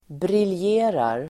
Ladda ner uttalet
Uttal: [brilj'e:rar]